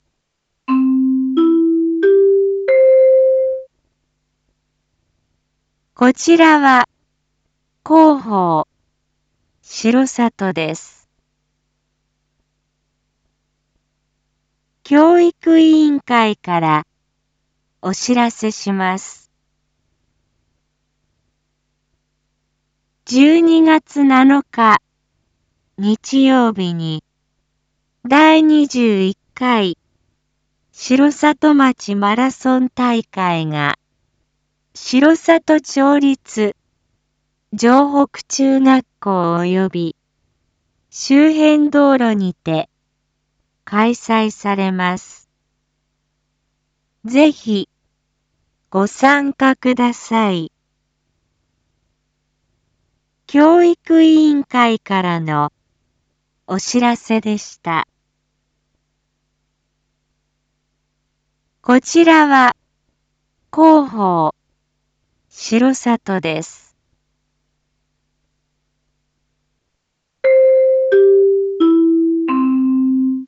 Back Home 一般放送情報 音声放送 再生 一般放送情報 登録日時：2025-11-10 07:01:15 タイトル：第21回城里町マラソン大会開催⑤ インフォメーション：こちらは広報しろさとです。